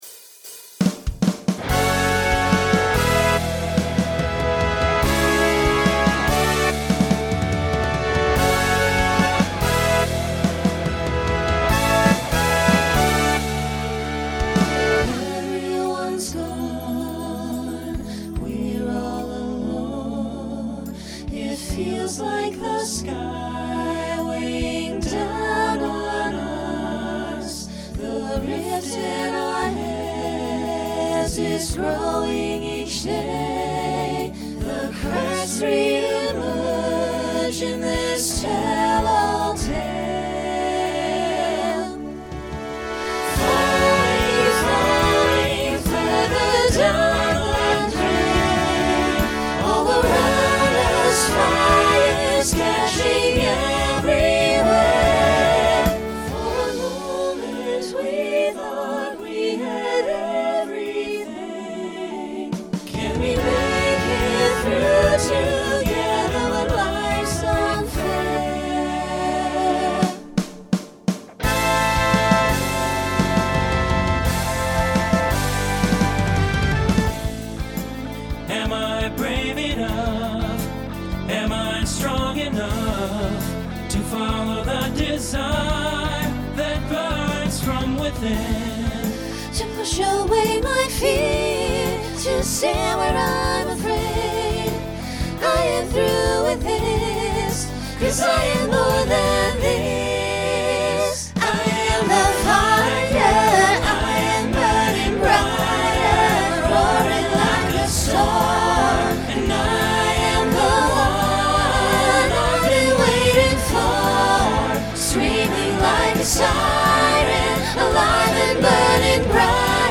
Genre Rock
Voicing SATB